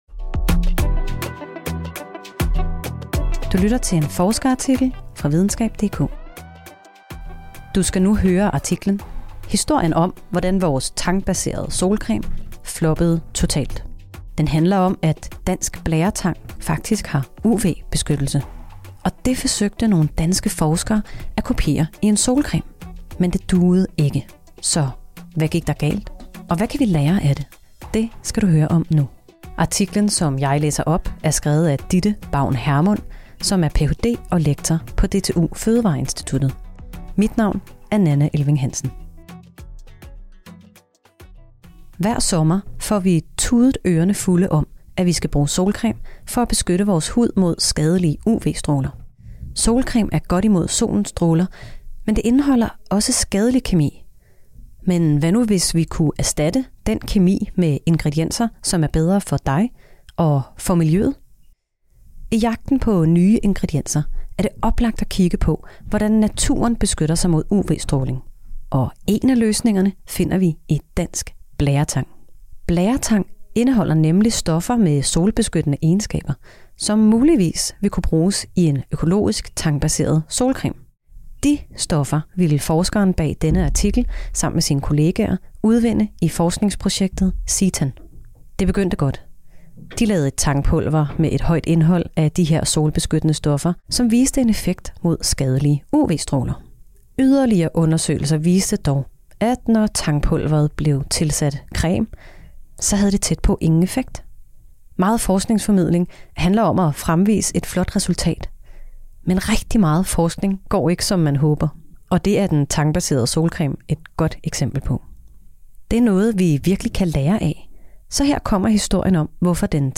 Det kan du høre i denne oplæste artikel.